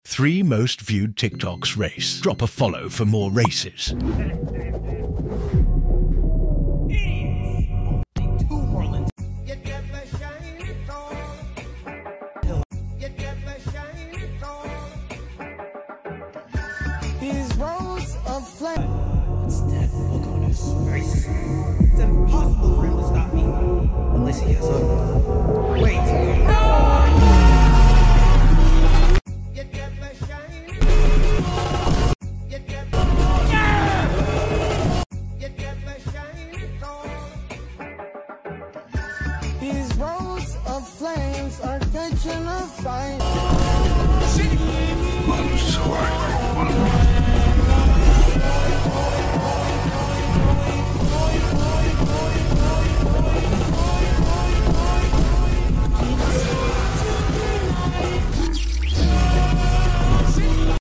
recorded & edited by me with custom effects, overlays, and commentary for entertainment